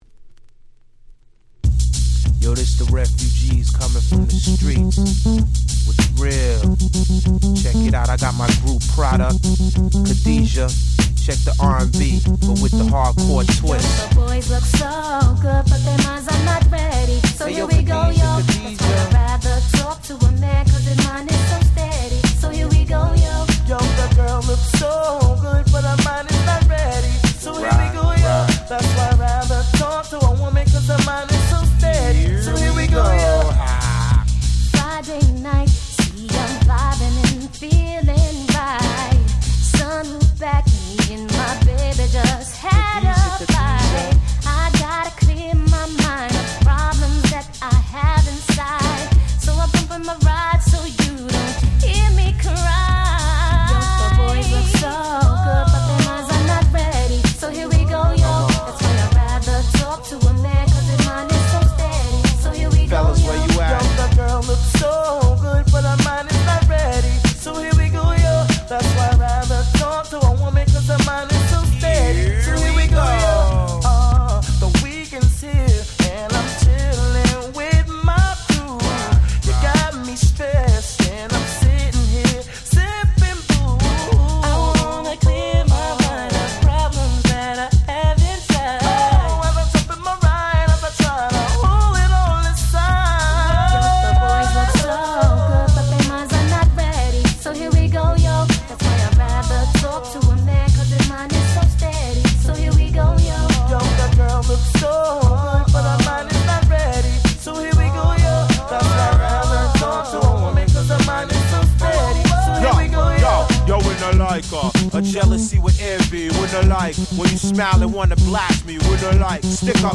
98' Smash Hit R&B !!